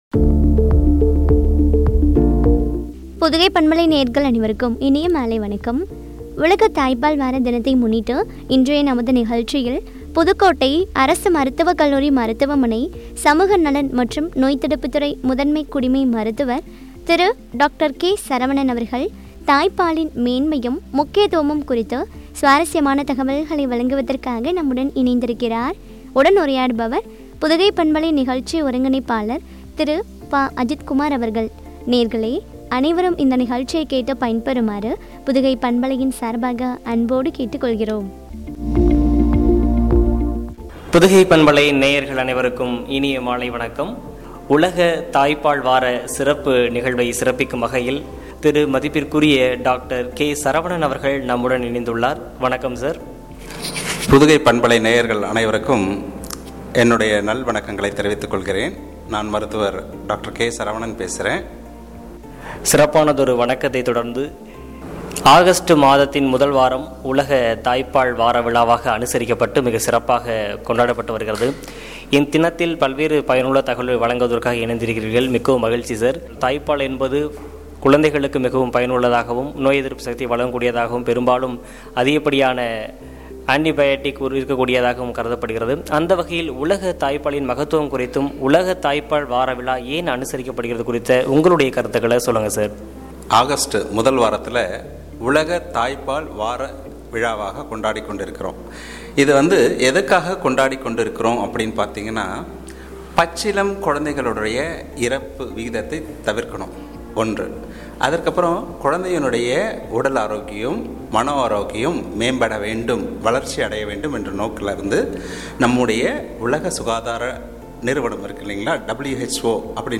முக்கியத்துவமும் குறித்து வழங்கிய உரையாடல்.